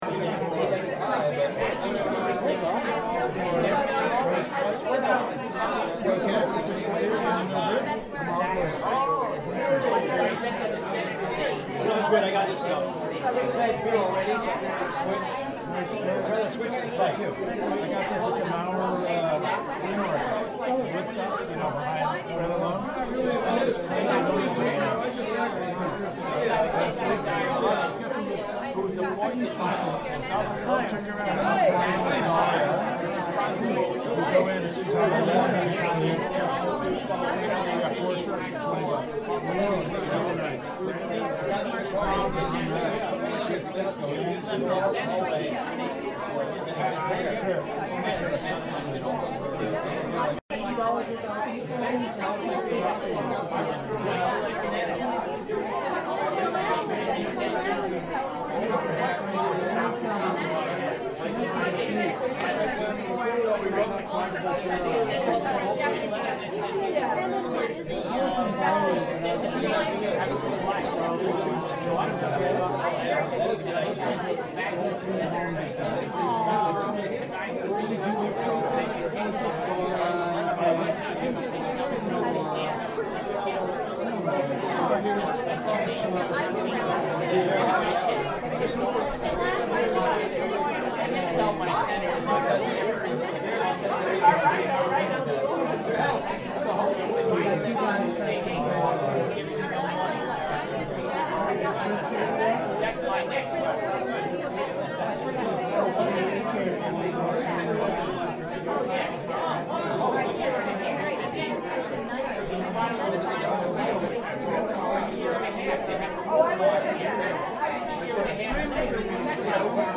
Разговоры людей для видеомонтажа